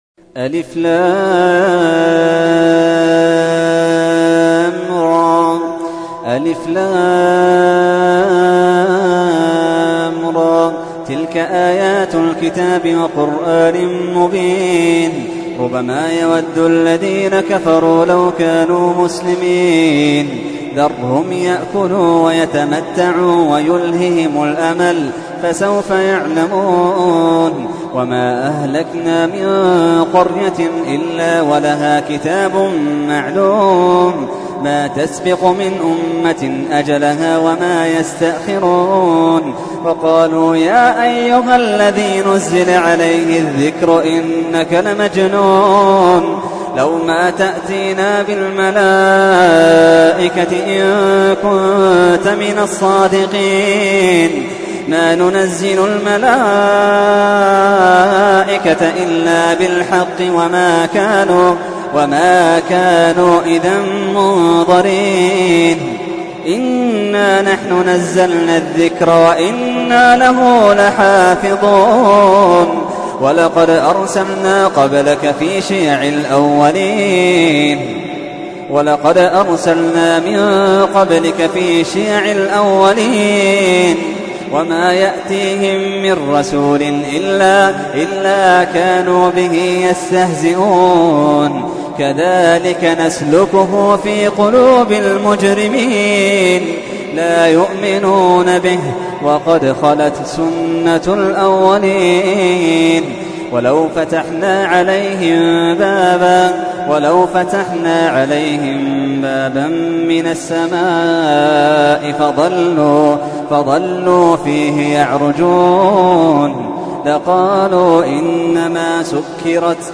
تحميل : 15. سورة الحجر / القارئ محمد اللحيدان / القرآن الكريم / موقع يا حسين